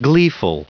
Prononciation du mot gleeful en anglais (fichier audio)
Prononciation du mot : gleeful